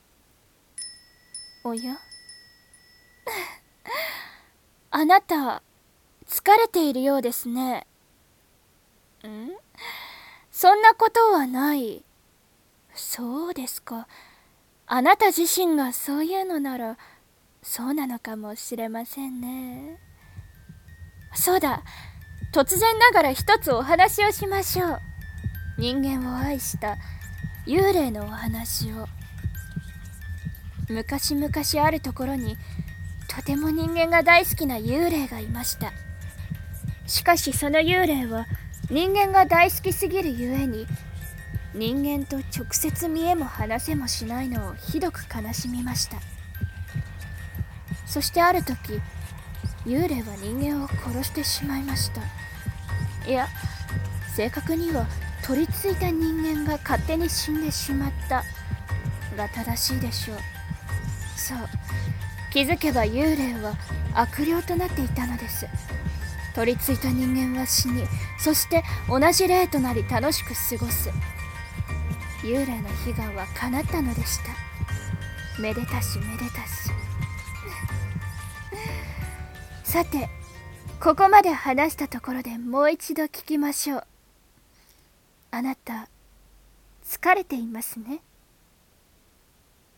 【声劇】あなた、つかれていますね？